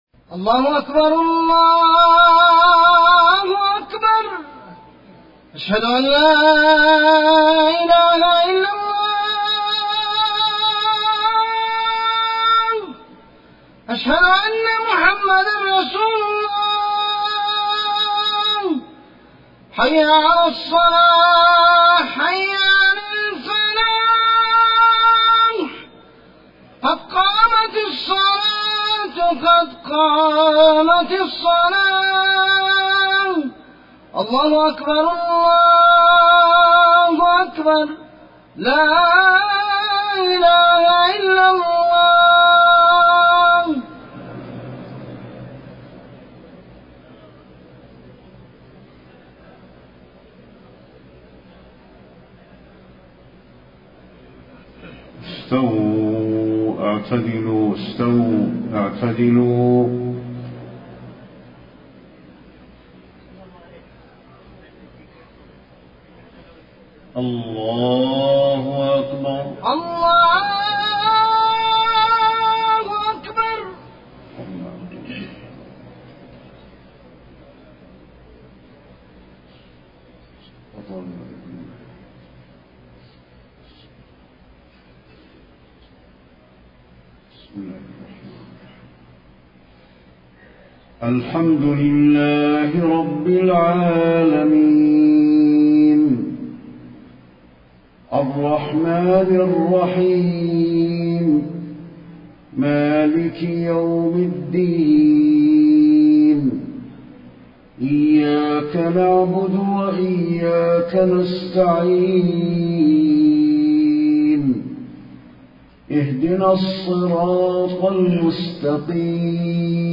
صلاة العشاء 2-9-1434 سورة الانفطار > 1434 🕌 > الفروض - تلاوات الحرمين